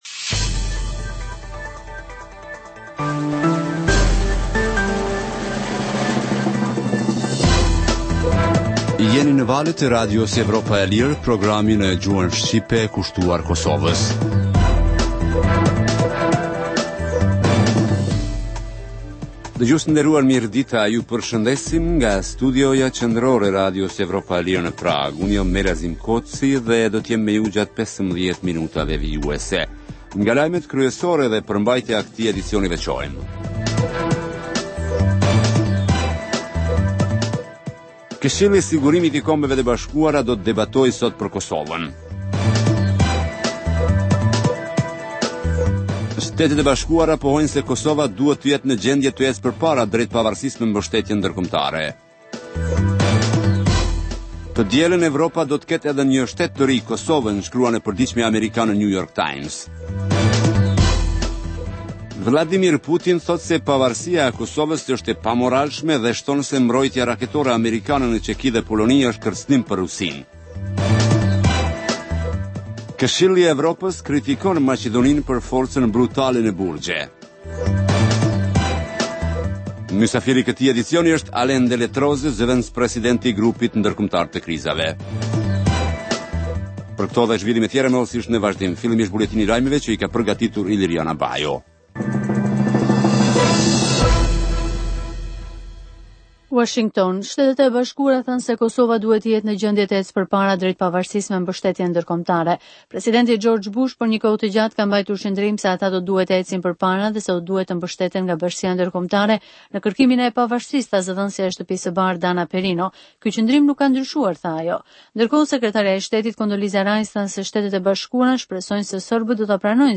Emisioni i mesditës fillon me buletinin e lajmeve që kanë të bëjnë me zhvillimet e fundit në Kosovë, rajon dhe botë. Në këtë emision sjellim raporte dhe kronika të ditës, por edhe tema aktuale nga zhvillimet politike dhe ekonomike. Emisioni i mesditës në të shumtën e rasteve sjellë artikuj nga shtypi perendimor, por edhe intervista me analistë të njohur ndërkombëtar kushtuar zhvillimeve në Kosovë dhe më gjërë.